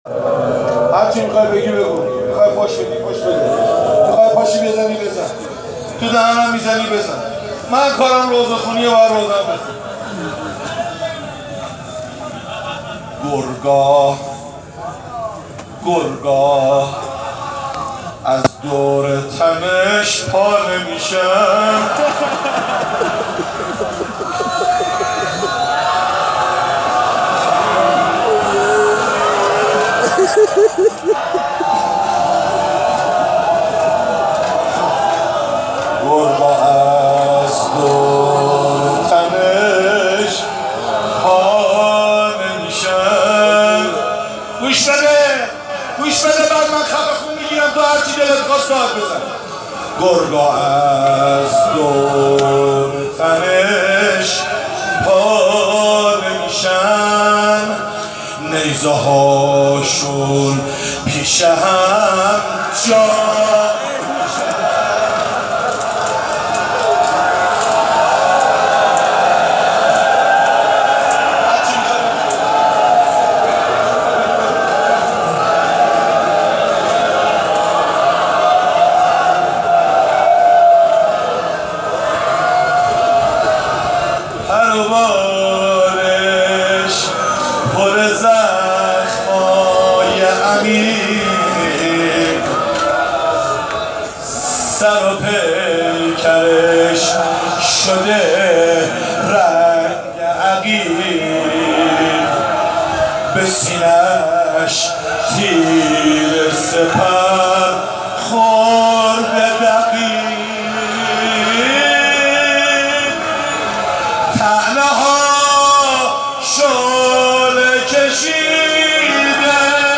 مراسم عصر عاشورا در بیت الرضا(ع) برگزار شد